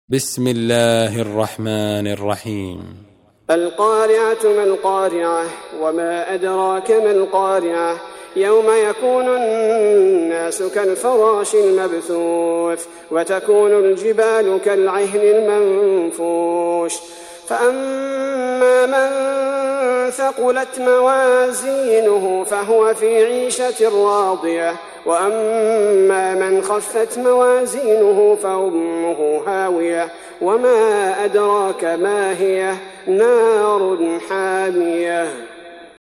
Surah Al-Q�ri'ah سورة القارعة Audio Quran Tarteel Recitation
Surah Sequence تتابع السورة Download Surah حمّل السورة Reciting Murattalah Audio for 101.